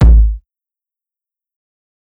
KICK_TWISTED_2.wav